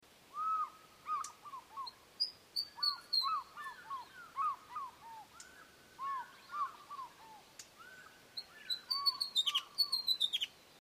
دانلود صدای عقاب دریایی از ساعد نیوز با لینک مستقیم و کیفیت بالا
جلوه های صوتی
برچسب: دانلود آهنگ های افکت صوتی انسان و موجودات زنده